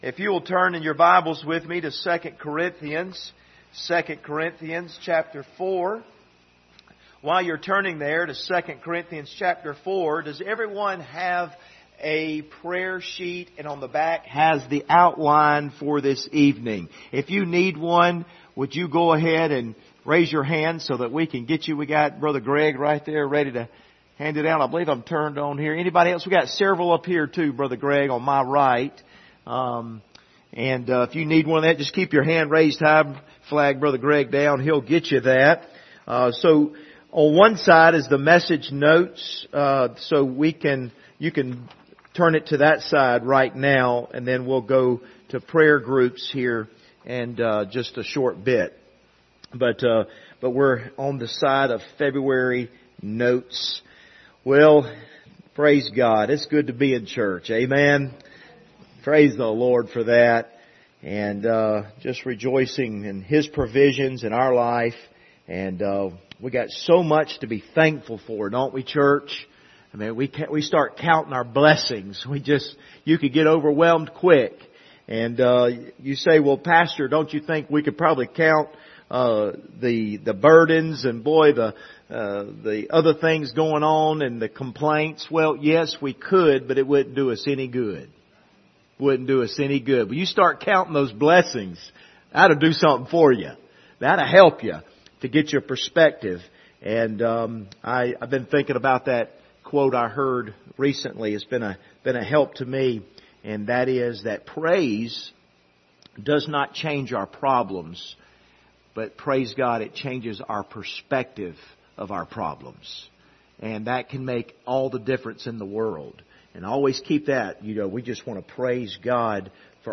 Passage: 2 Corinthians 4:7 Service Type: Wednesday Evening